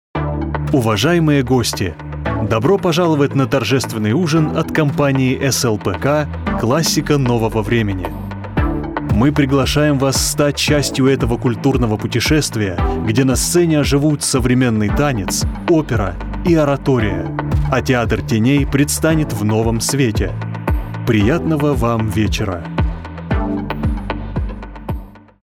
Une prestation rapide et de qualité studio pour des résultats exceptionnels.
Annonces
Rode NT-1; Audience ID14
Baryton